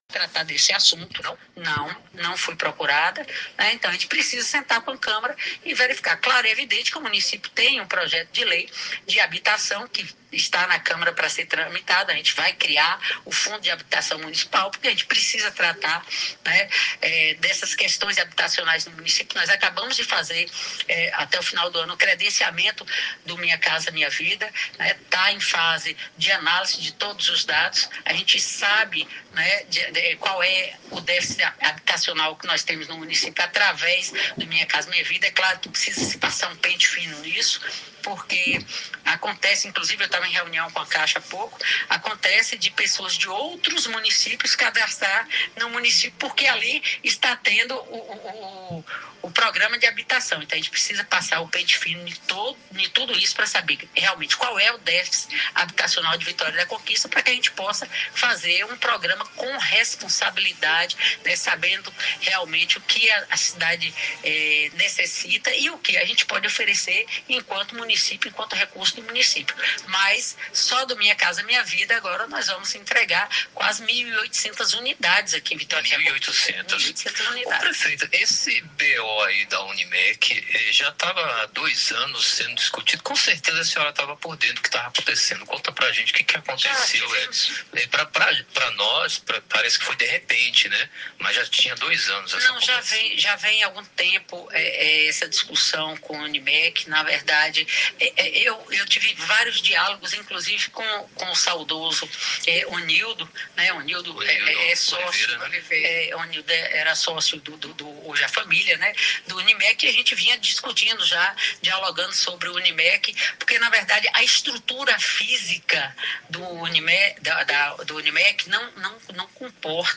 Durante entrevista à Rádio Clube, a prefeita de Vitória da Conquista, Sheila Lemos, comentou temas que impactam diretamente a vida da população: habitação, saúde e relação com a Câmara de Vereadores. Além de apresentar números, ela defendeu planejamento, responsabilidade fiscal e diálogo institucional.